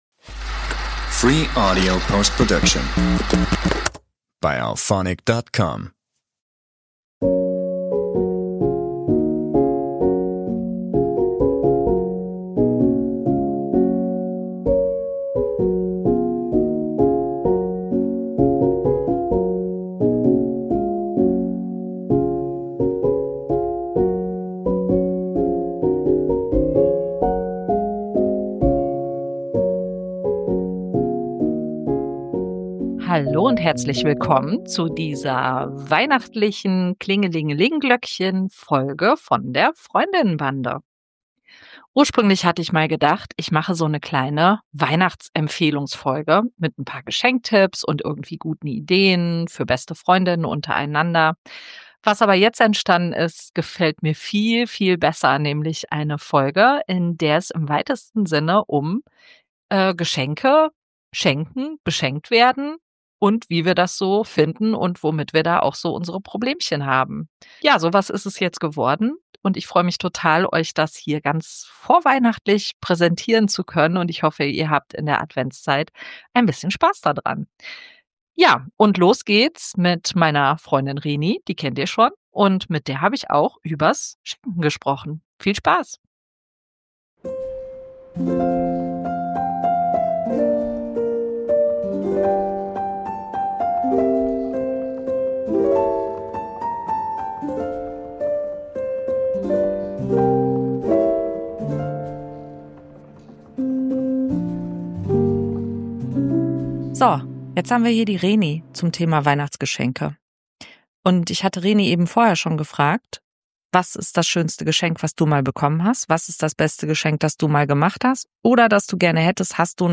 Hier habe ich eine wunderschöne Weihnachtsfolge zum Thema Schenken zusammengestellt. 10 Frauen berichten, wie sie es denn mit dem Schenken so halten.